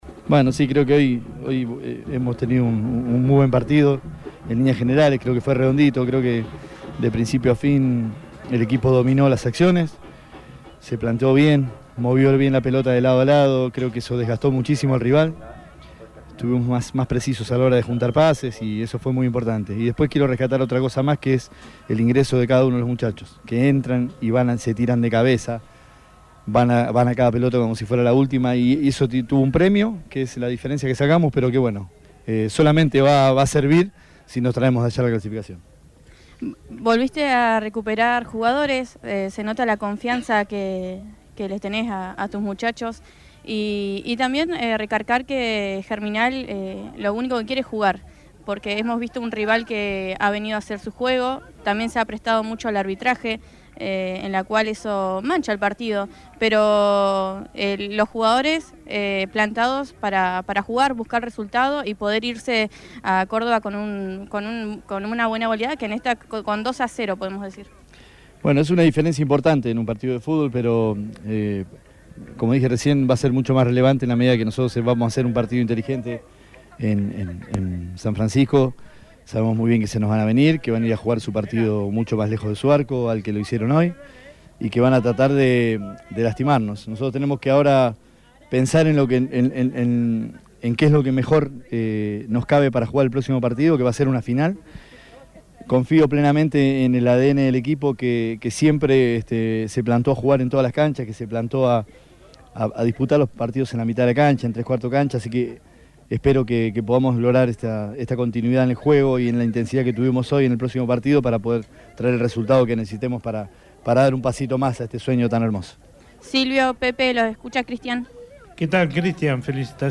Este se el testimonió que le brindó al equipo de transmisión de FM Bahía Engaño al término del partido.